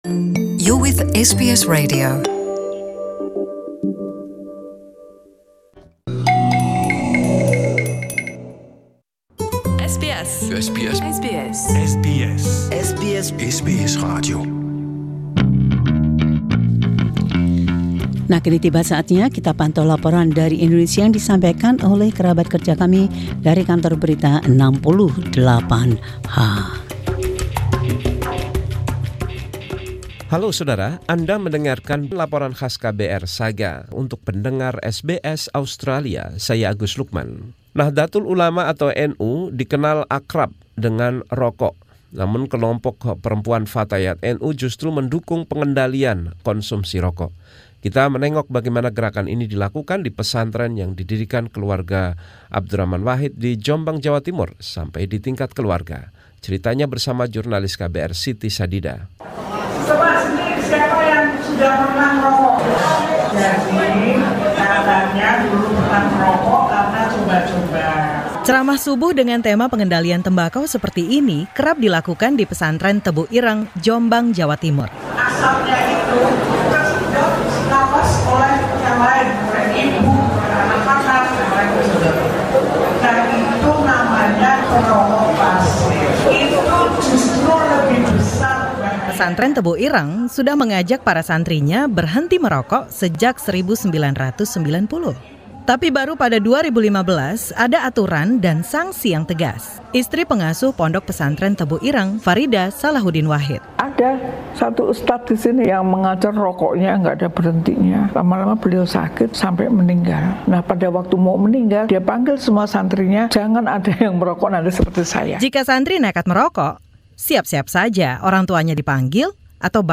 KBR 68H reports.